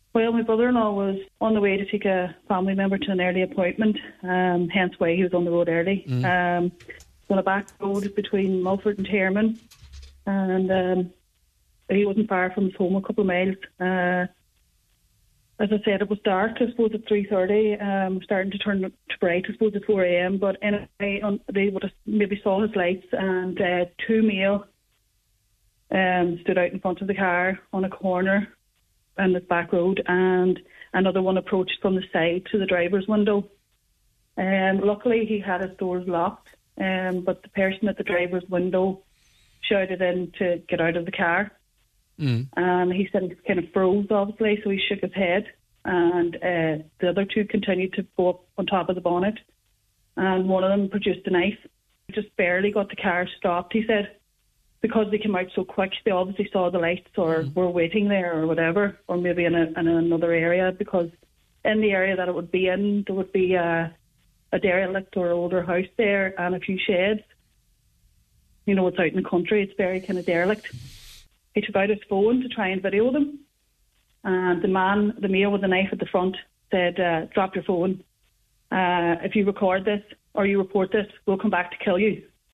Speaking on today’s Nine til Noon Show